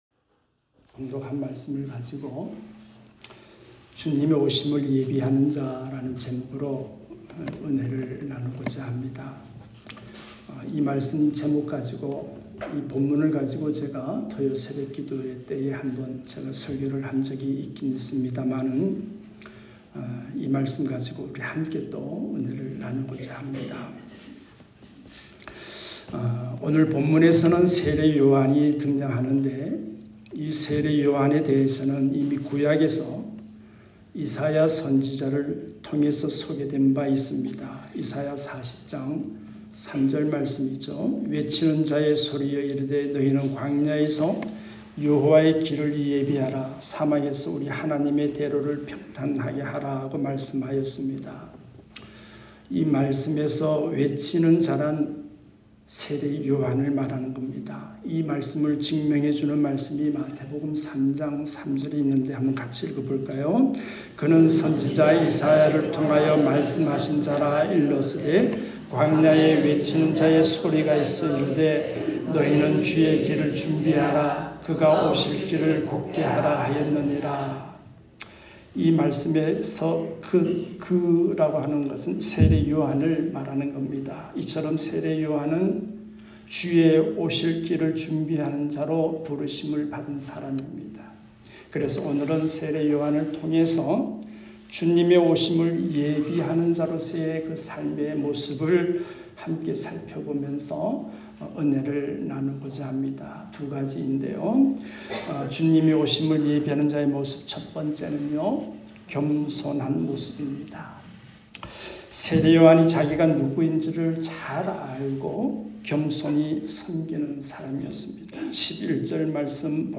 주님의 오심을 예배하는 자 ( 마3:11-15 ) 말씀